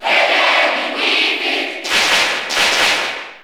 Category: Crowd cheers (SSBU) You cannot overwrite this file.
Wii_Fit_Trainer_Cheer_Italian_SSB4_SSBU.ogg